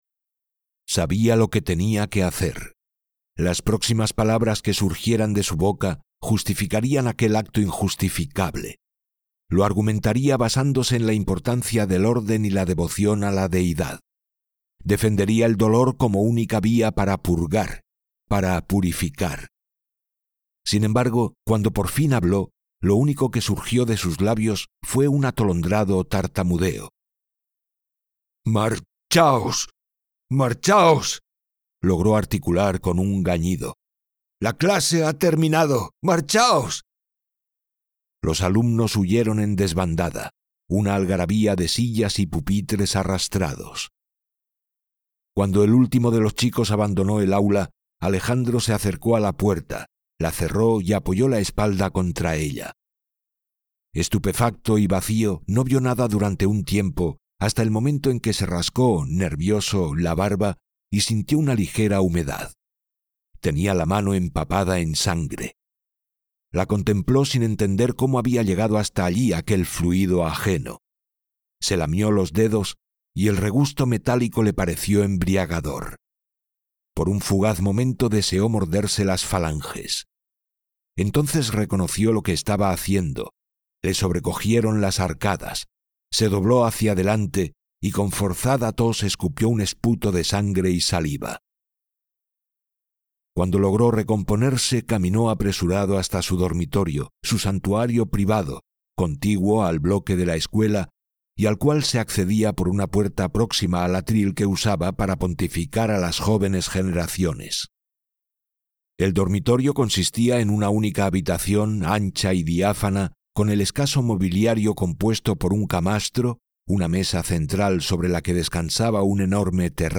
Audiolibro La catedral de la carne (The Cathedral of Flesh)